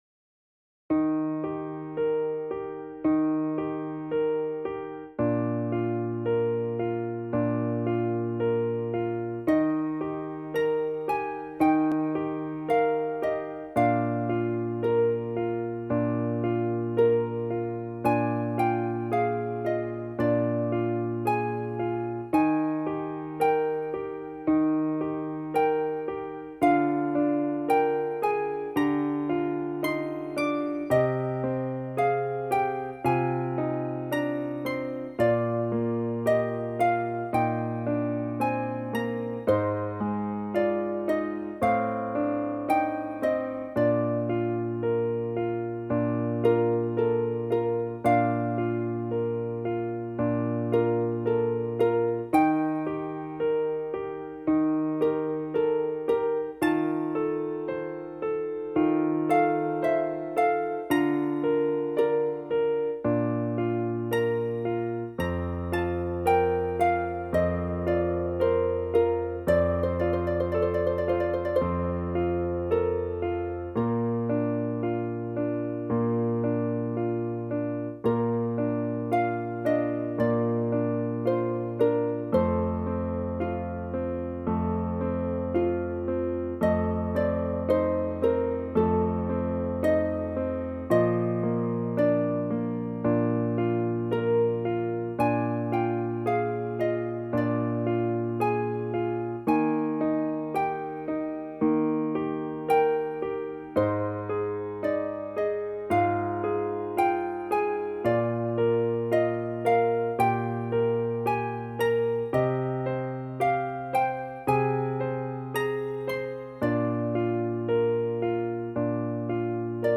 DIGITAL SHEET MUSIC - FLUTE with PIANO ACCOMPANIMENT
Flute Solo, Classical
piano with slower practice version and faster performance